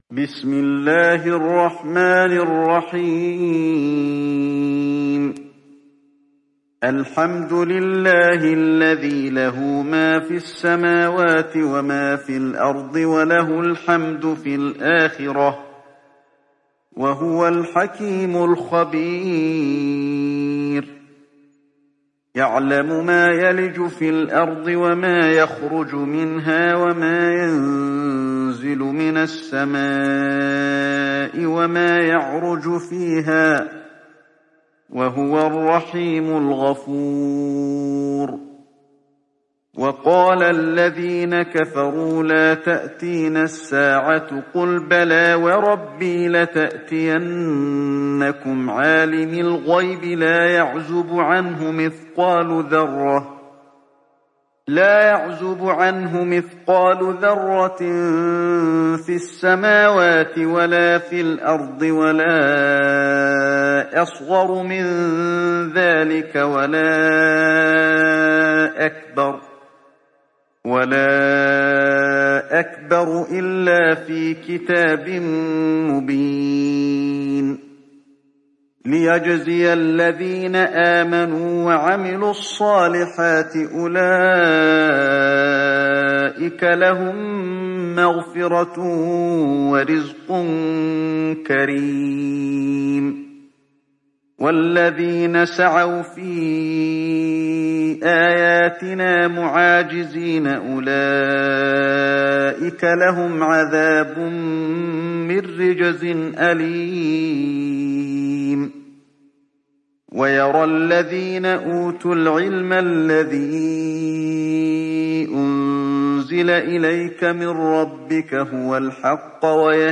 تحميل سورة سبأ mp3 بصوت علي الحذيفي برواية حفص عن عاصم, تحميل استماع القرآن الكريم على الجوال mp3 كاملا بروابط مباشرة وسريعة